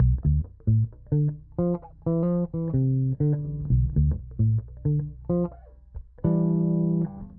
爵士贝斯
描述：平滑的爵士乐低音循环 Dm
Tag: 130 bpm Jazz Loops Bass Guitar Loops 639.27 KB wav Key : D